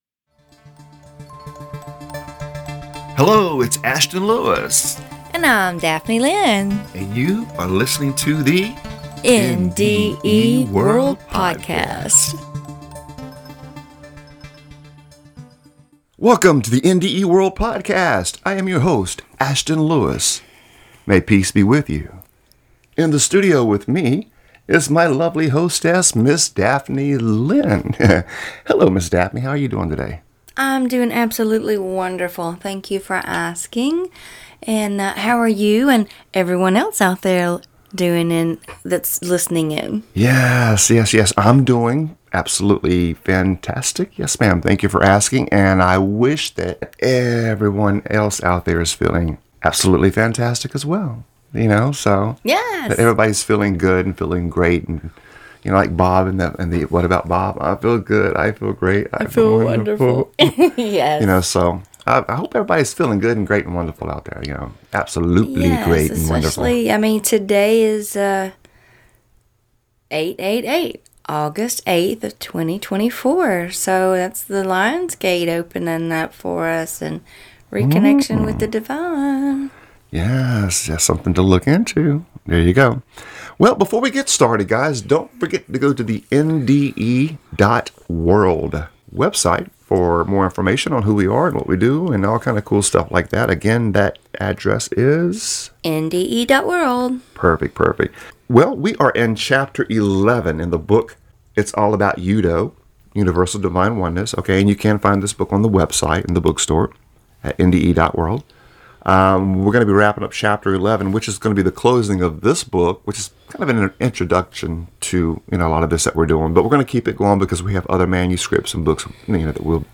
In this series, we will share the world of UDO pronounced (yoo' dō), with our listeners. UDO is the acronym for Universal Divine Oneness, and we will help you to reach that state of UDO.